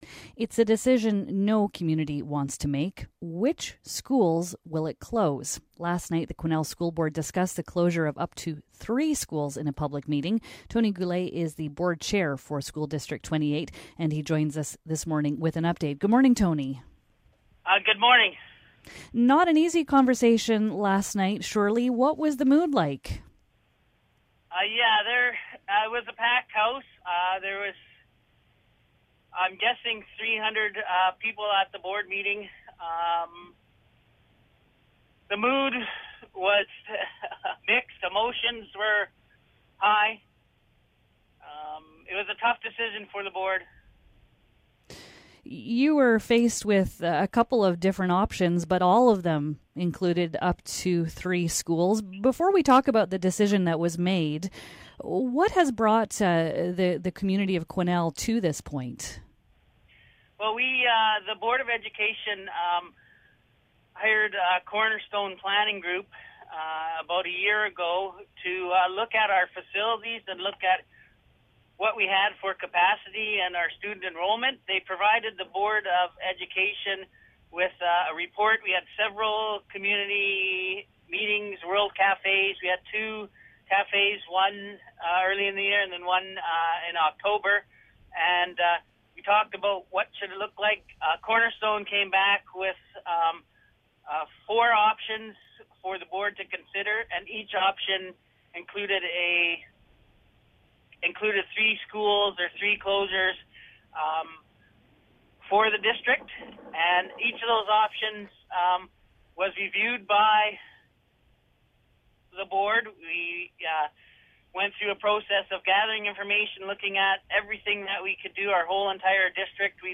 The Quesnel school board has recommended closing three elementary schools to deal with declining enrollment: Parkland, Kersley, and Ecole Baker. We speak to board chair Tony Goulet about the decision.